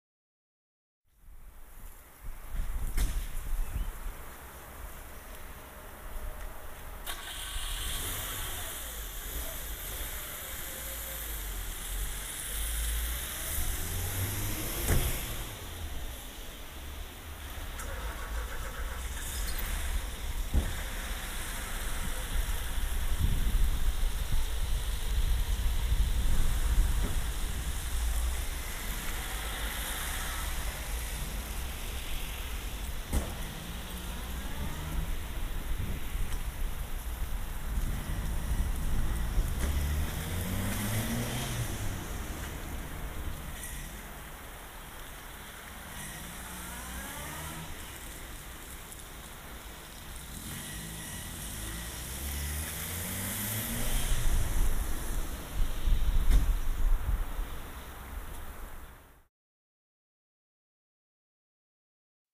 5P, Door slamming + car starting
Parkeringsplads, hvor der inden for 55 sek. kommer 7 bilister, som smækker en dør, starter motoren og kører. Midt på optagelsen forekommer vindstøj i mikrofonen.
Door slamming + car starting